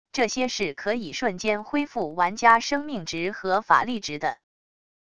这些是可以瞬间恢复玩家生命值和法力值的wav音频